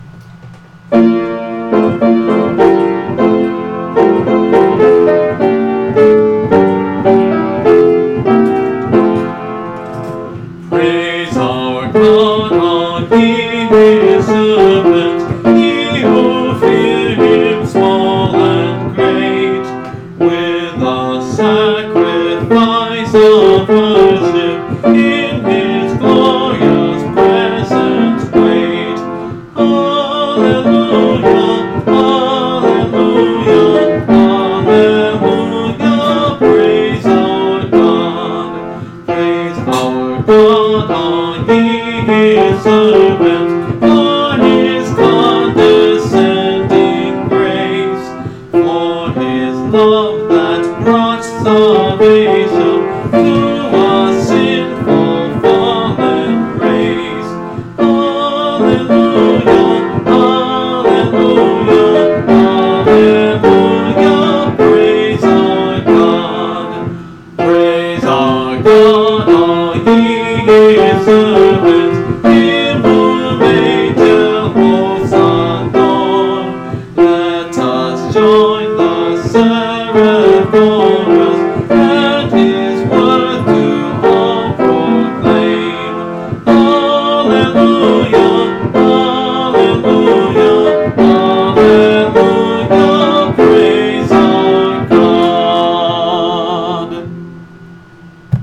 (Part of a series singing through the hymnbook I grew up with: Great Hymns of the Faith)
Creational and relational type Renaissance man (writer, singer, thinker) holding to C.S Lewis's credo that if you follow truth originality takes care of itself, (anyway, originality is so yesterday, anyway.)